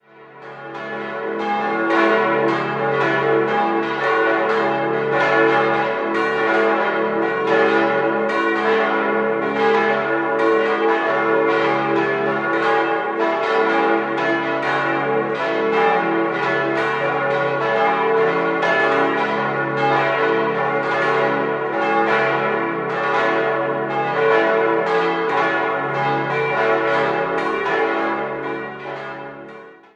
5-stimmiges ausgefülltes und erweitertes Cis-Moll-Geläute: cis'-e'-fis'-gis'-h'
bell
Im rechten Turm befinden sich die drei alten Glocken, die beiden neuen sind im linken Turm (Uhrturm) untergebracht.